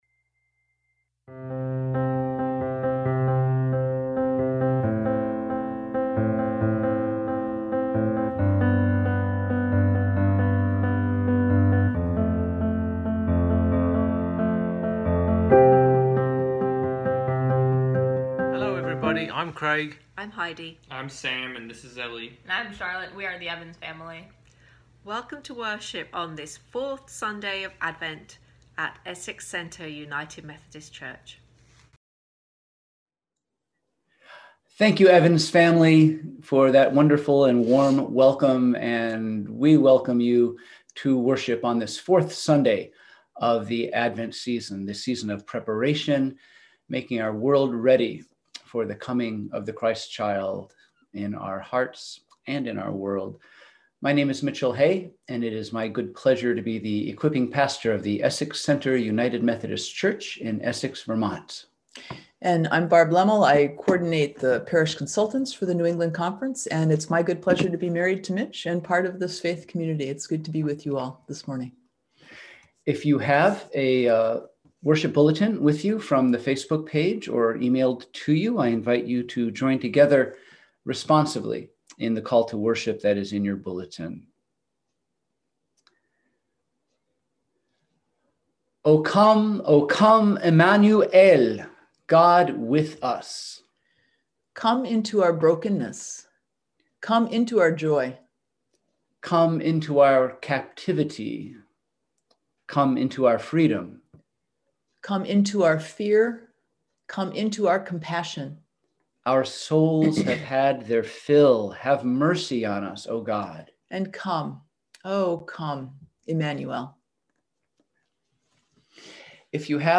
We held virtual worship on Sunday, December 20, 2020 at 10:00am!